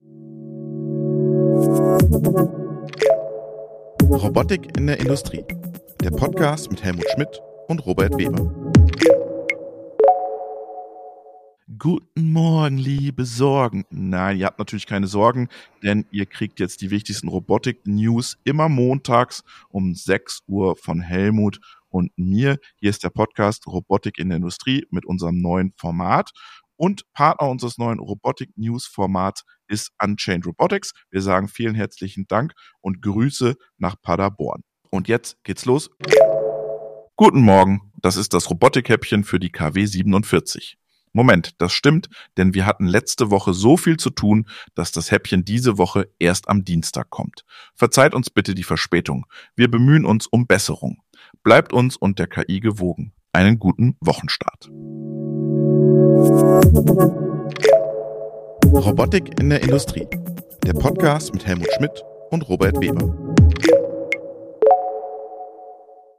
Unser Newshäppchen für die Fahrt zur Arbeit. Immer Montags gibt es die Robotik News - mit-recherchiert, geschrieben und gesprochen mit und von einer KI.